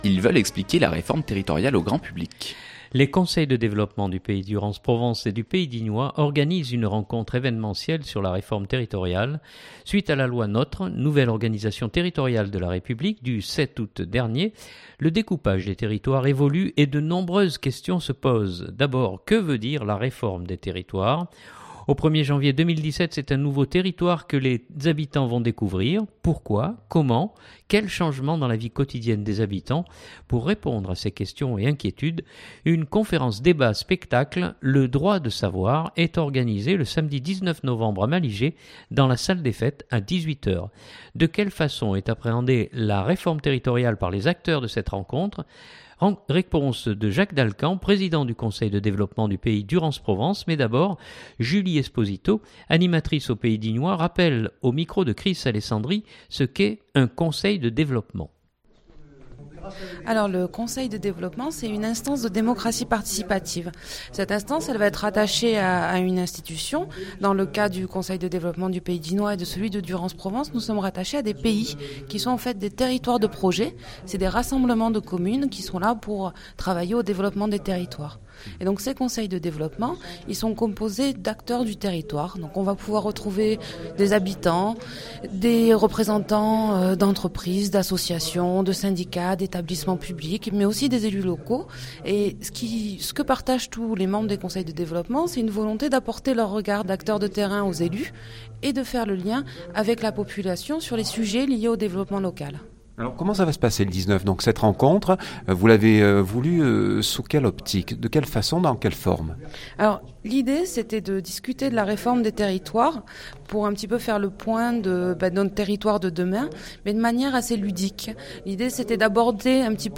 Journal du 2016-11-10 Réforme Territoriale.mp3 (3.38 Mo)